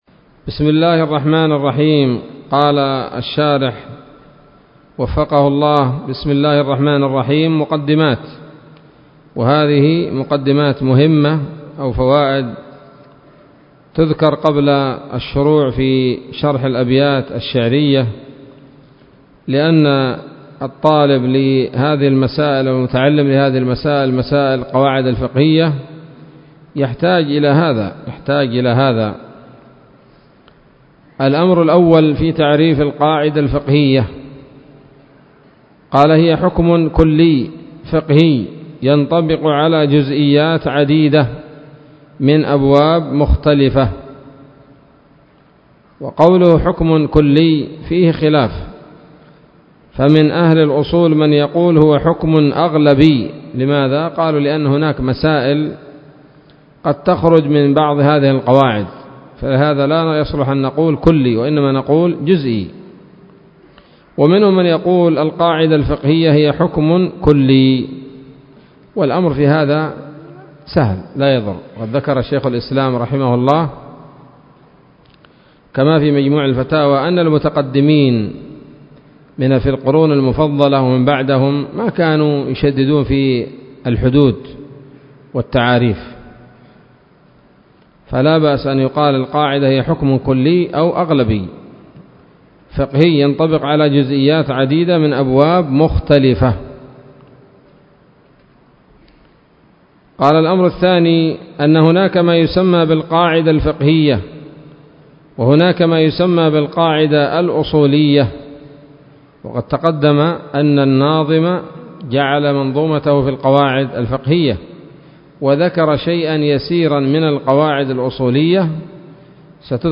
الدرس الثاني من الحلل البهية في شرح منظومة القواعد الفقهية